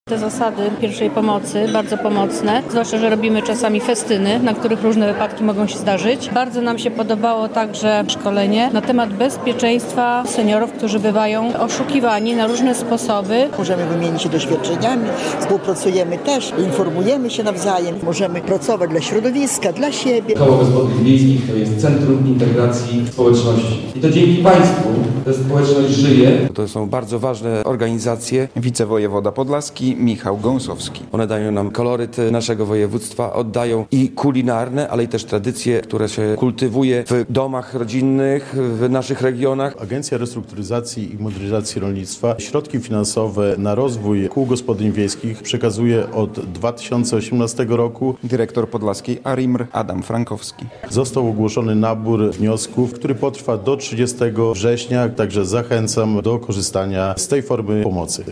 Szkolenie ARiMR dla KGW - relacja